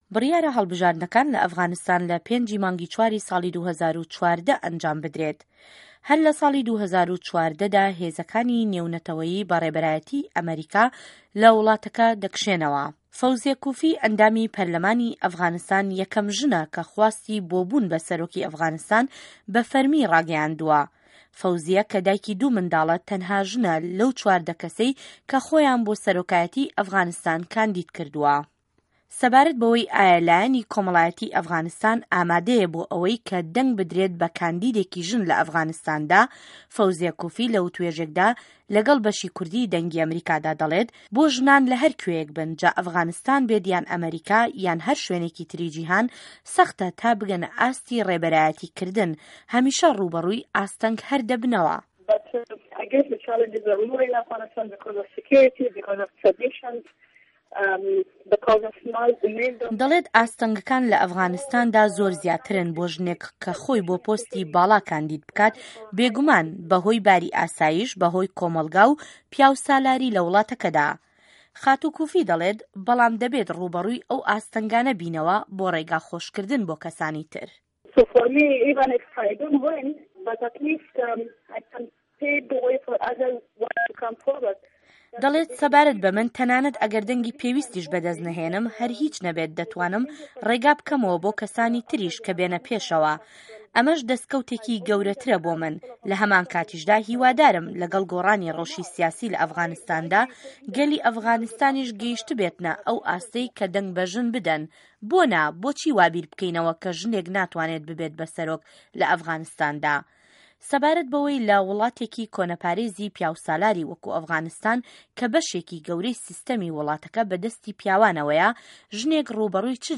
وتووێژ له‌گه‌ڵ فه‌وزییه‌ کوفی